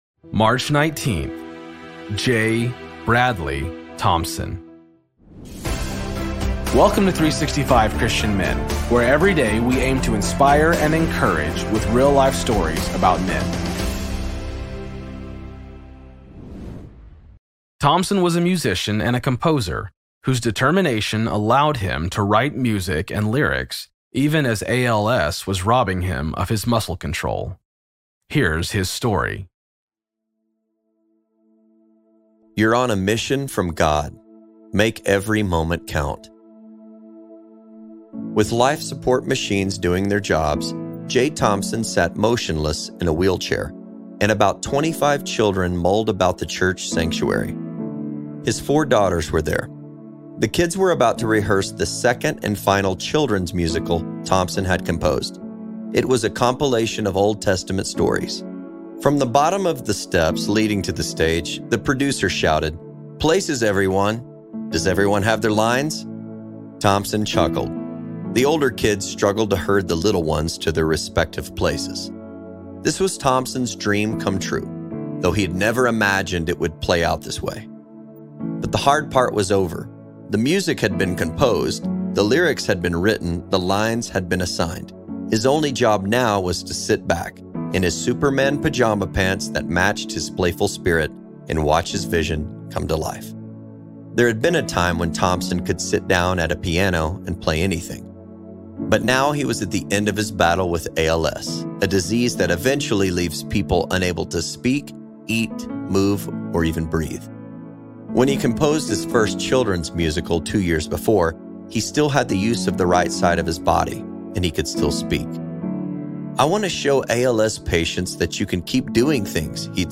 Story read